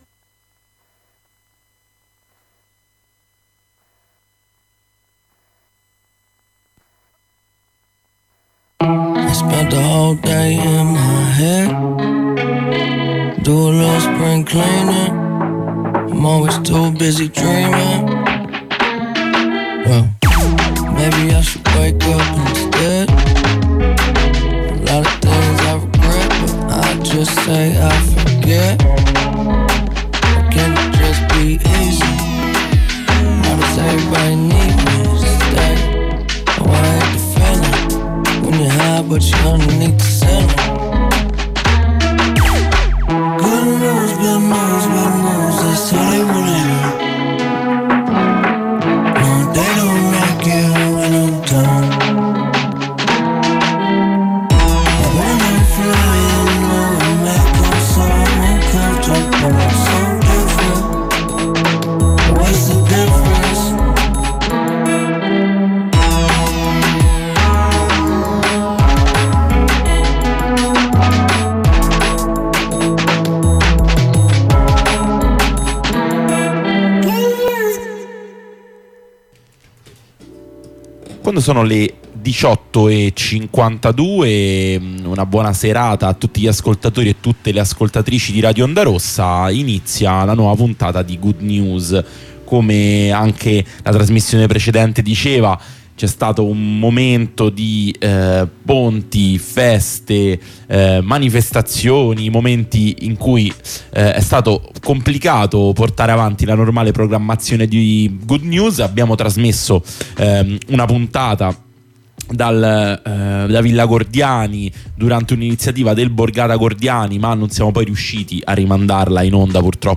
Nelle puntante playlist vi accompagniamo nella scoperta dei brani che sono in rotazione nelle cuffie di noi redattrici e redattori di Good News. In apertura alla settimana che ci porterà allo sciopero dell’8 marzo, una selezione di artiste e musiche da ogni parte del mondo, esplorando tanti generi diversi: dall'rnb all’afrobeat, dal funk all'elettronica.